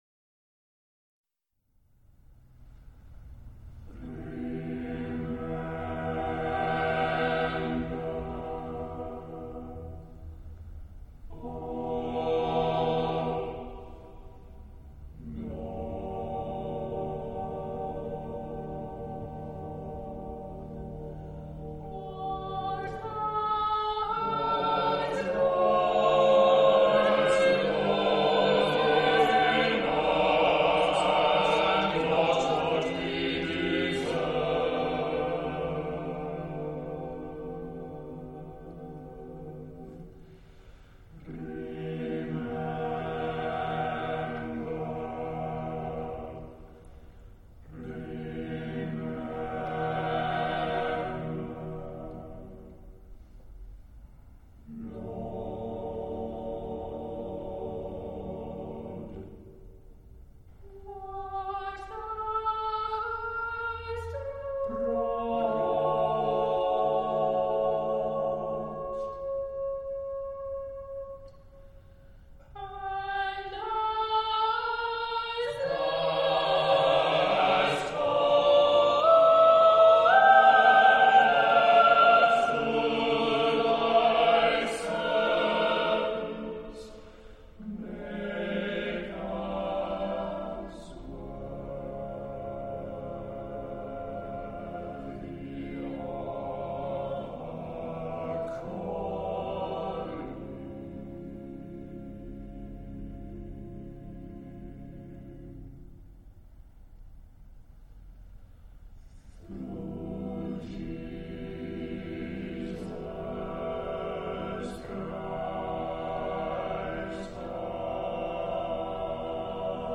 Genre-Stil-Form: geistlich ; Chor
Charakter des Stückes: intensiv
Chorgattung: SSAATTBB  (8 gemischter Chor Stimmen )
Tonart(en): frei